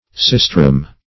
sistrum - definition of sistrum - synonyms, pronunciation, spelling from Free Dictionary
Search Result for " sistrum" : The Collaborative International Dictionary of English v.0.48: Sistrum \Sis"trum\, [L., fr. Gr.